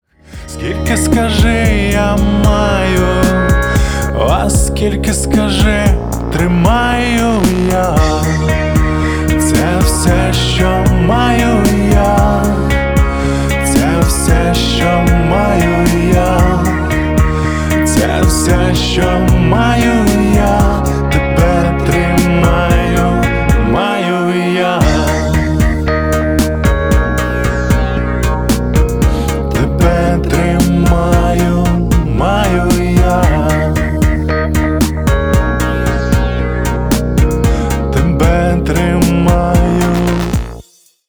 • Качество: 235, Stereo
мужской вокал
лирические
украинский рок
баллада
поп-рок